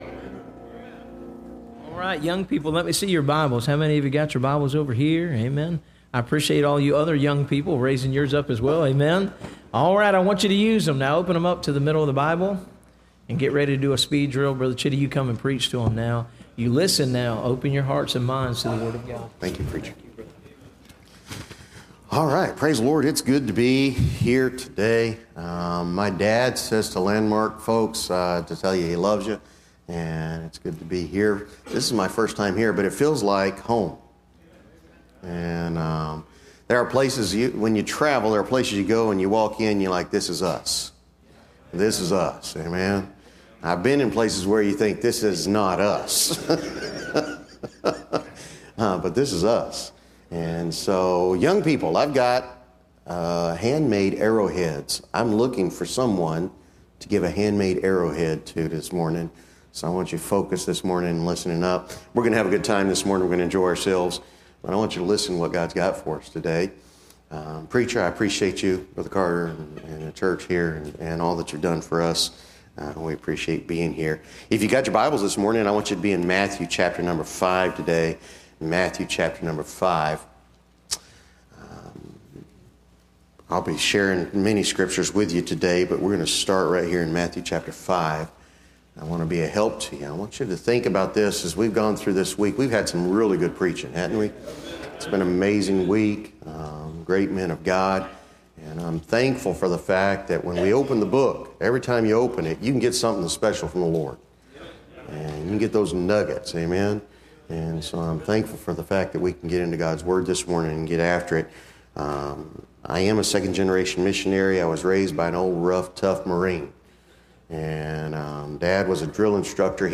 Listen to Message
Service Type: Bible Conference